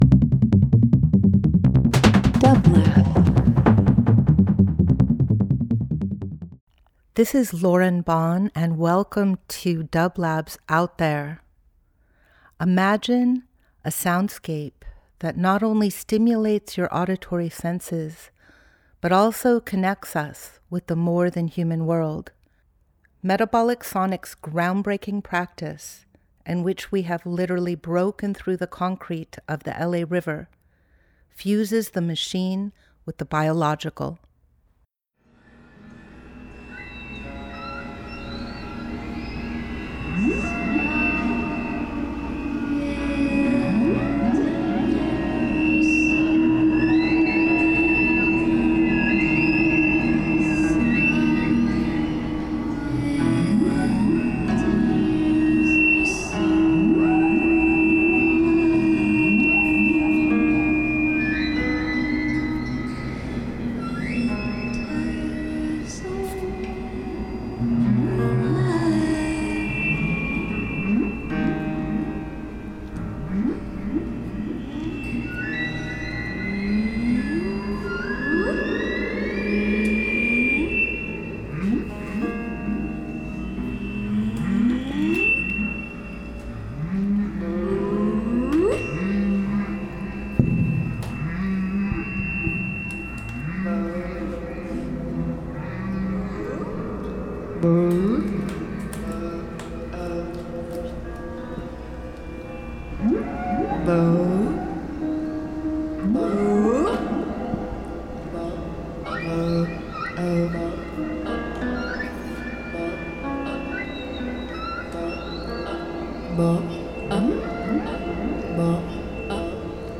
devotional practice of improvising music
Recorded in spring of 2024 during final construction phases
Ambient Experimental Field Recording Fourth World